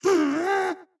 Minecraft Version Minecraft Version 25w18a Latest Release | Latest Snapshot 25w18a / assets / minecraft / sounds / mob / happy_ghast / hurt6.ogg Compare With Compare With Latest Release | Latest Snapshot
hurt6.ogg